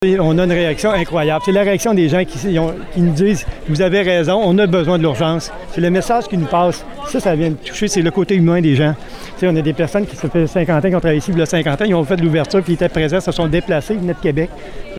Environ 500 personnes ont pris part à une marche à Fortierville vendredi après-midi pour que le gouvernement maintienne l’intégralité des services à l’urgence.
Ce dernier ne cachait pas son émotion devant le nombre de manifestants présents pour l’occasion.